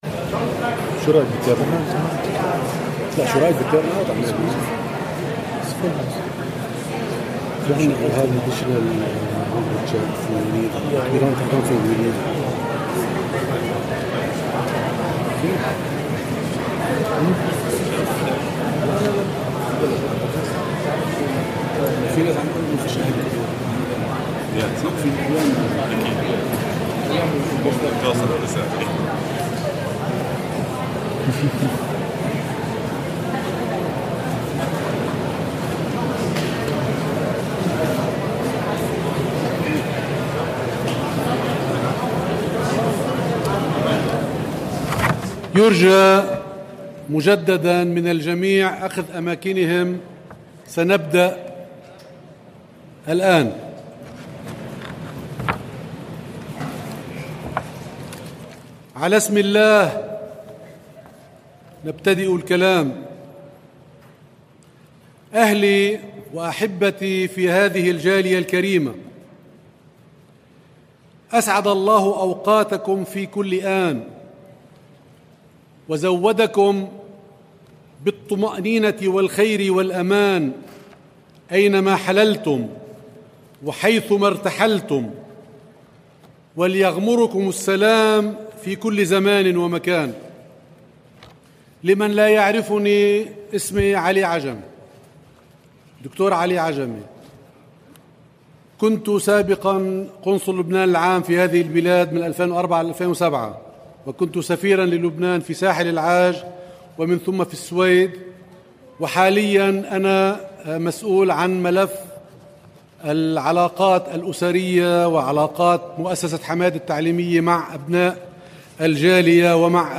first-education-conference-part-1.mp3